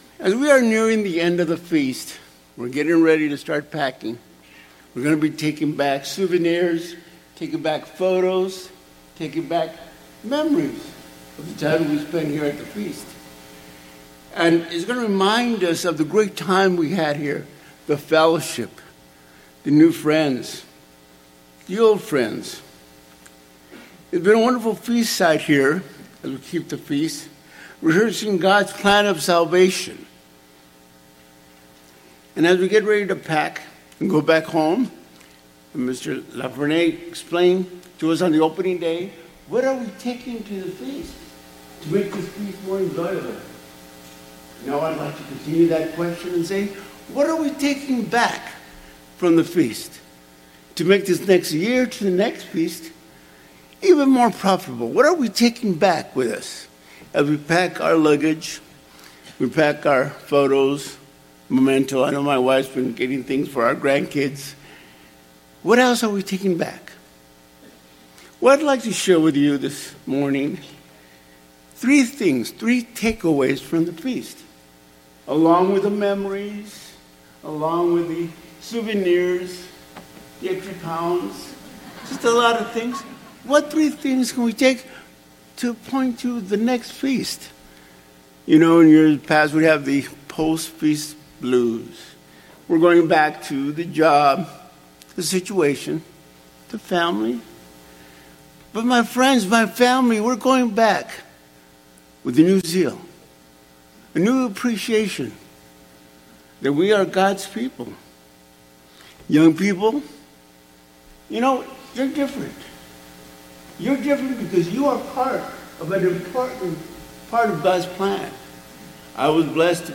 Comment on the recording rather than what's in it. Given in Rapid City, South Dakota